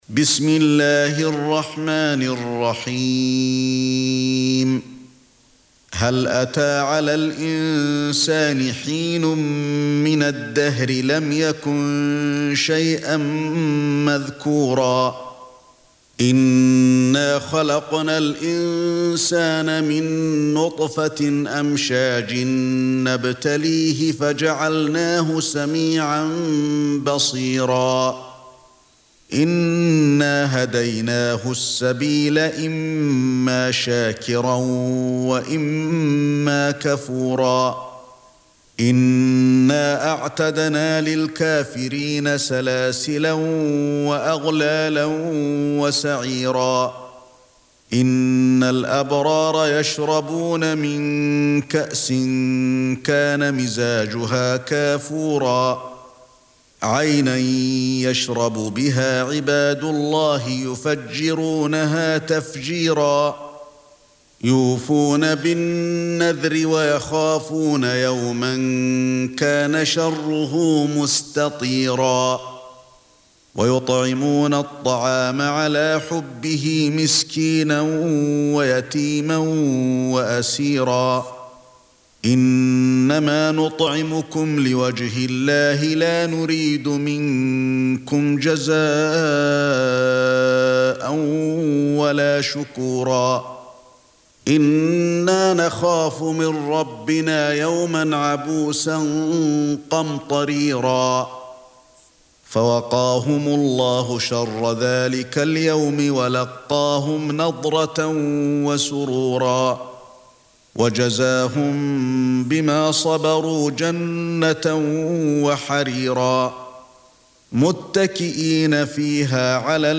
Surah Sequence تتابع السورة Download Surah حمّل السورة Reciting Murattalah Audio for 76. Surah Al-Ins�n or Ad-Dahr سورة الإنسان N.B *Surah Includes Al-Basmalah Reciters Sequents تتابع التلاوات Reciters Repeats تكرار التلاوات